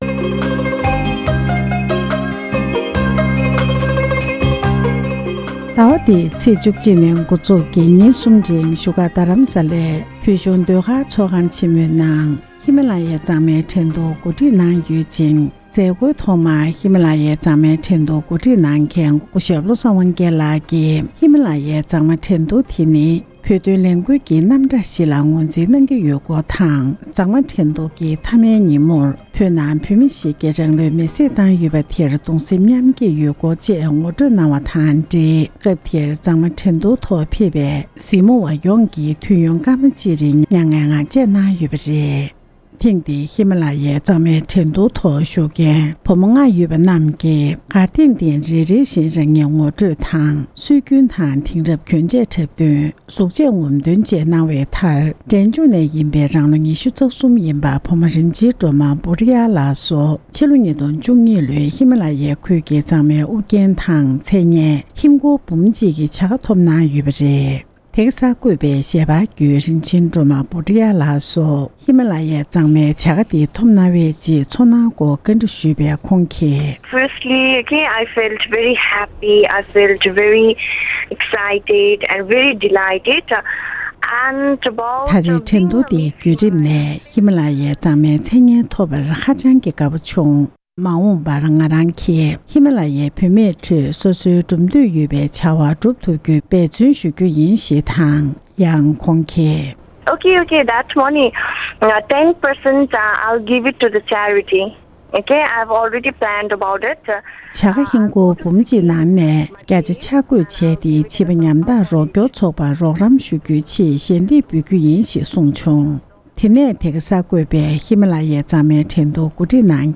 འབྲེལ་ཡོད་མི་སྣར་གནས་འདྲི་ཞུས་ཏེ་གནས་ཚུལ་ཕྱོགས་སྒྲིག་ཞུས་པ་ཞིག་ལ་གསན་རོགས་ཞུ༎